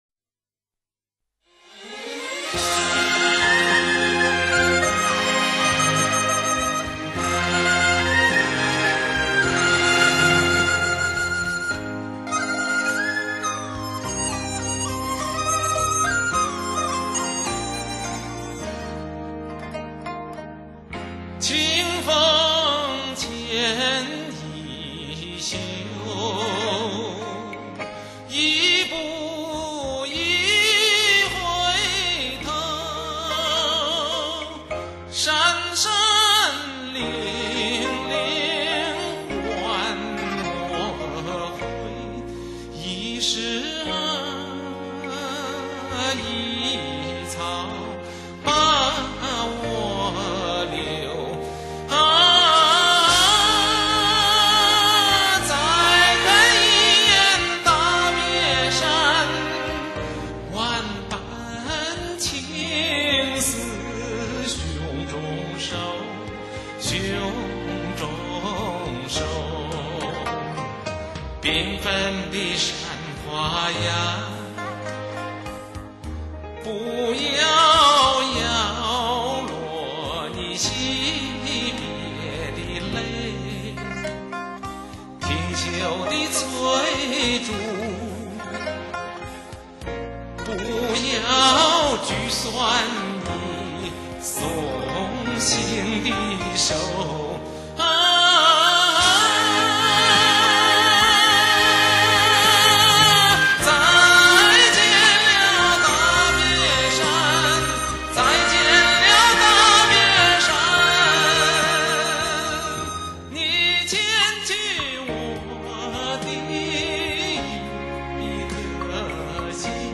按民族、美声、通俗不同风格精心编排，曲曲动人，它可以说是本世纪中国声乐发展的缩影，无论欣赏、收藏决不会令您失望！